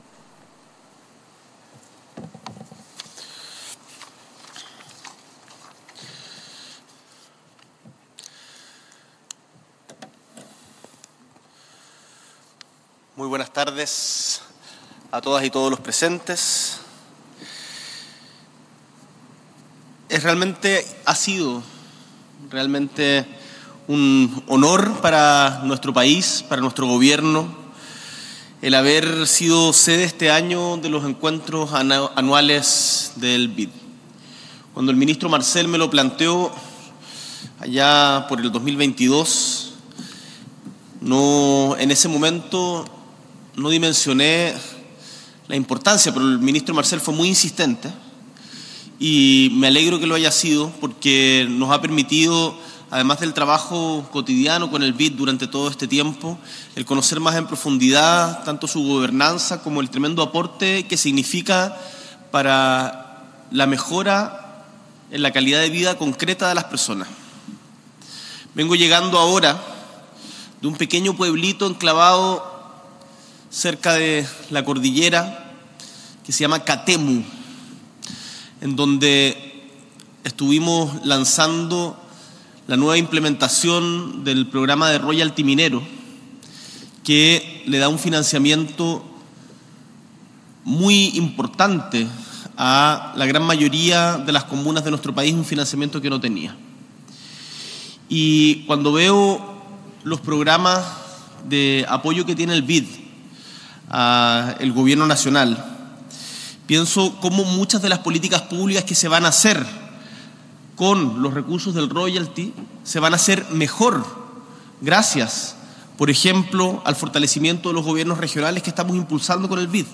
S.E. el Presidente de la República, Gabriel Boric Font, participa de la sesión inaugural de la Reunión Anual de la Asamblea de Gobernadores del BID
S.E. el Presidente de la República, Gabriel Boric Font, junto al ministro de Hacienda, Mario Marcel, y el Presidente del Banco Interamericano de Desarrollo (BID), Ilan Goldfajn, participa de la sesión inaugural de la Reunión Anual de la Asamblea de Gobernadores del BID.
Discurso